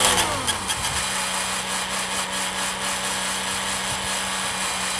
rr3-assets/files/.depot/audio/sfx/transmission_whine/trans_off_low.wav